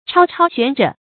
超超玄着 chāo chāo xuán zhù
超超玄着发音